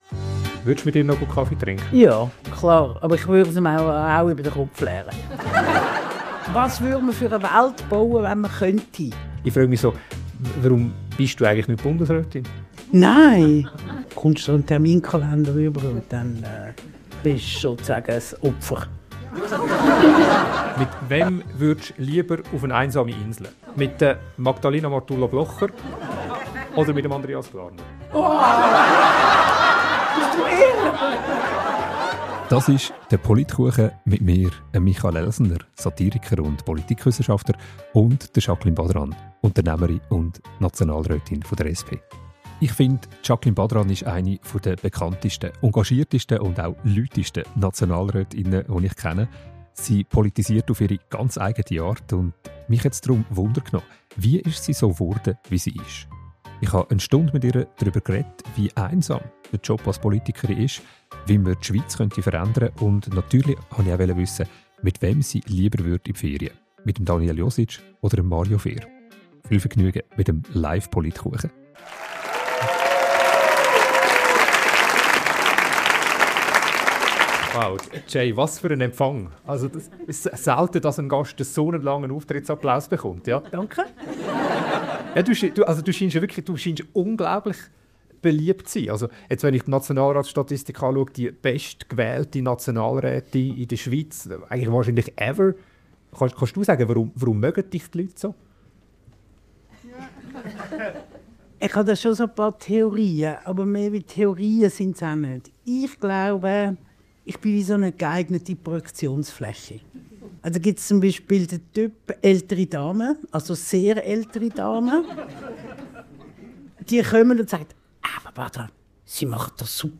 Beschreibung vor 9 Monaten Satiriker & Politikwissenschafter Michael Elsener fragt SP-Nationalrätin Jacqueline Badran: Wenn sie die Königin der Schweiz wäre: Was würde sie sofort einführen? In dieser Spezialfolge POLITKUCHEN – mit Live-Publikum, dafür ohne Kuchen – erzählt Jacqueline Badran, wie einsam der Job als Politiker:in ist und warum sie nicht Bundesrätin wird.